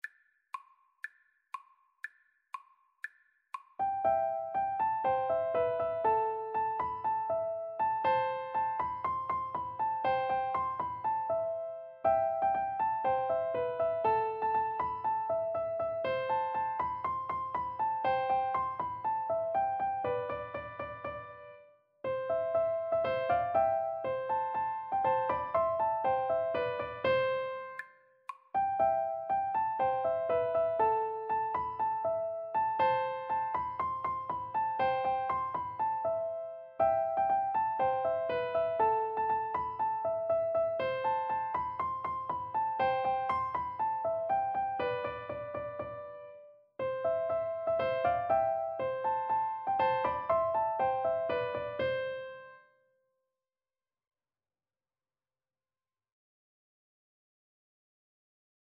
Play (or use space bar on your keyboard) Pause Music Playalong - Player 1 Accompaniment transpose reset tempo print settings full screen
C major (Sounding Pitch) (View more C major Music for Piano Duet )
Piano Duet  (View more Easy Piano Duet Music)